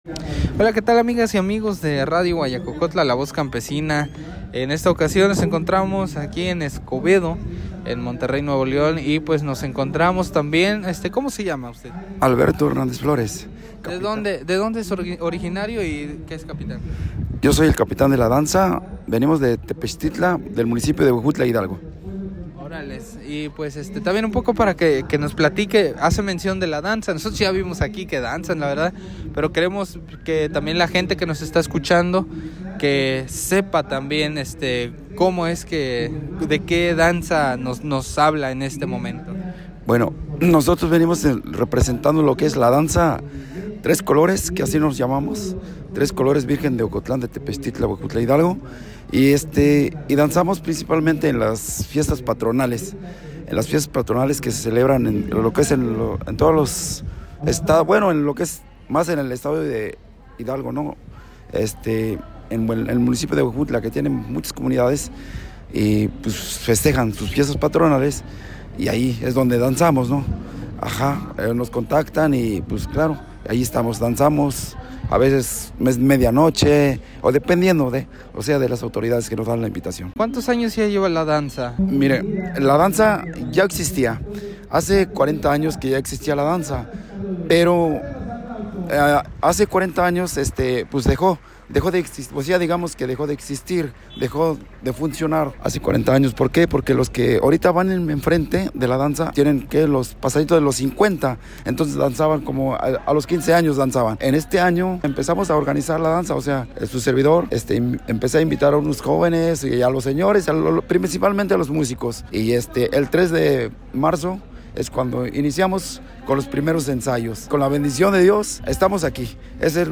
El reporte